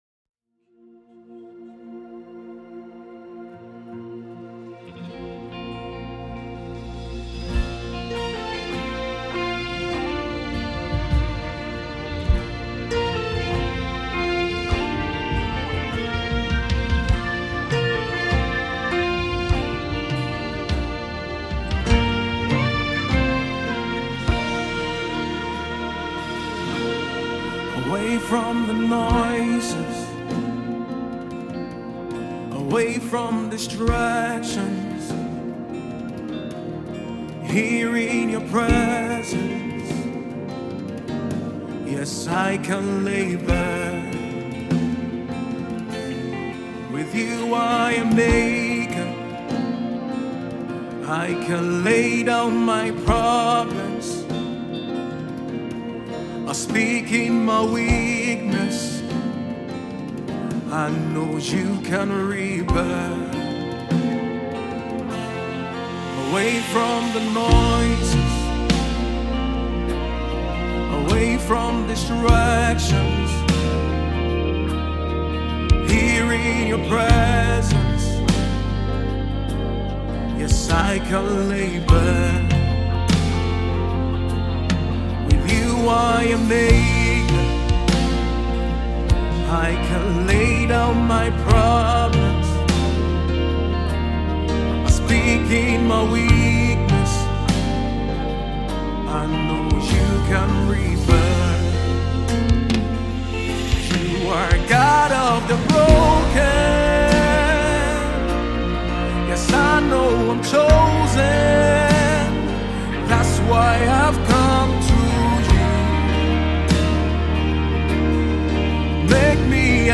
Genre: Gospel/Christian.